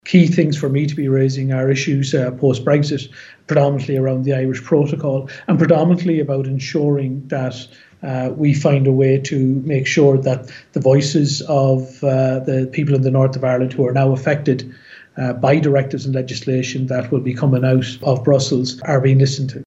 Sinn Fein’s Chris MacManus is an MEP for Midlands North-West, and a member of the EU delegation. He says outstanding issues related to Brexit and the Northern Ireland Protocol must be addressed……….